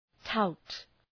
Προφορά
{taʋt}